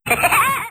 Worms speechbanks
jump2.wav